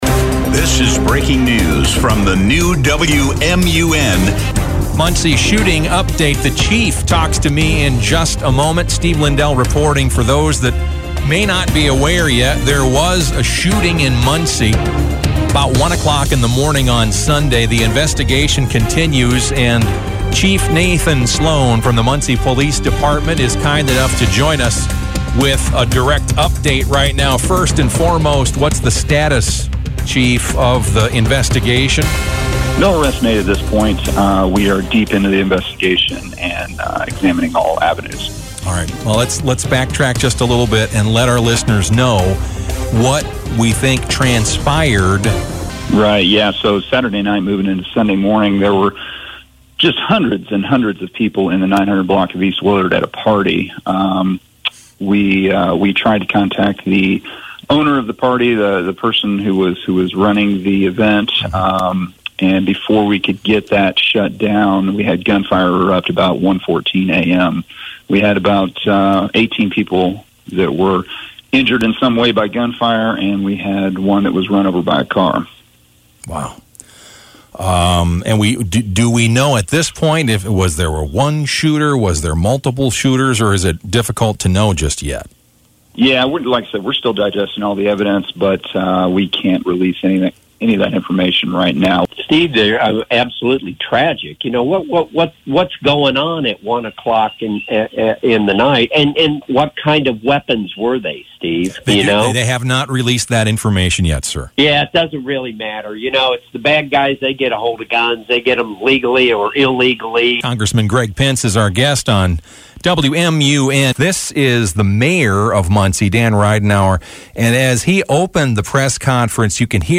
Best Radio General News StoryWMUN-AM/FM (Muncie) – Muncie Shooting Update